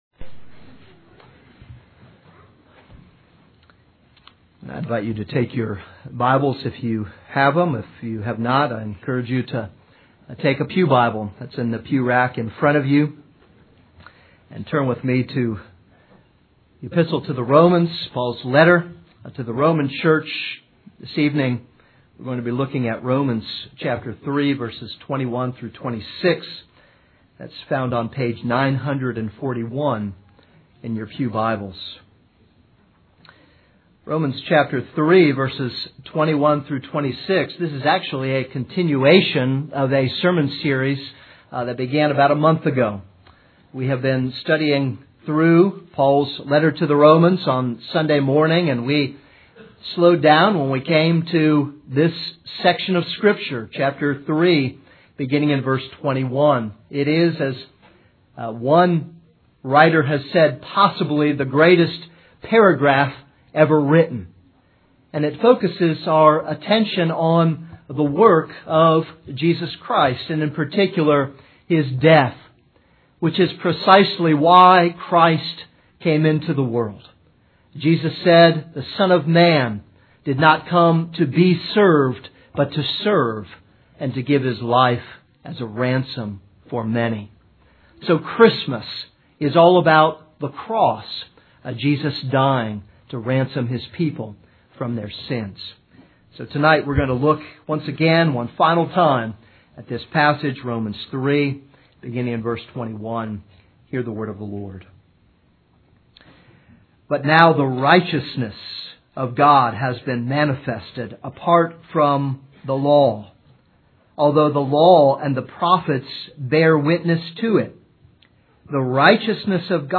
This is a sermon on Romans 3:21-26.